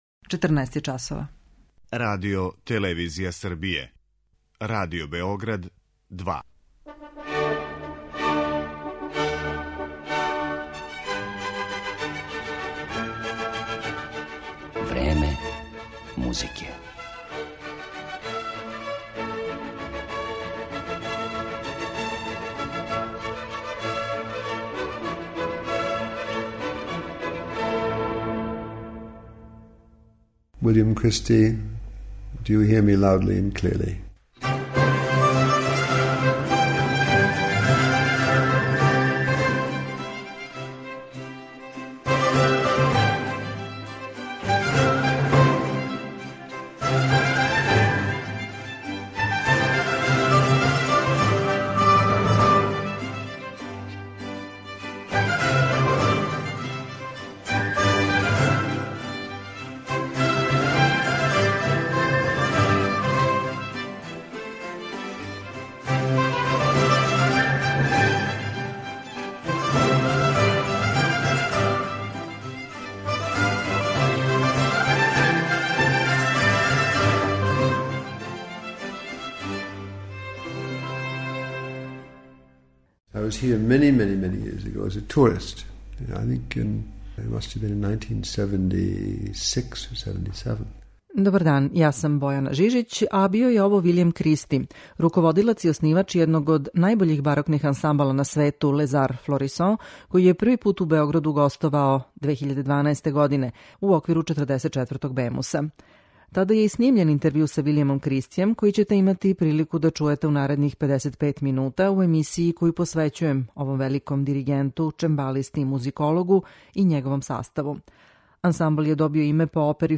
У њој ћете имати прилику да чујете и интервју остварен са Вилијамом Кристијем непосредно пред њихов први београдски концерт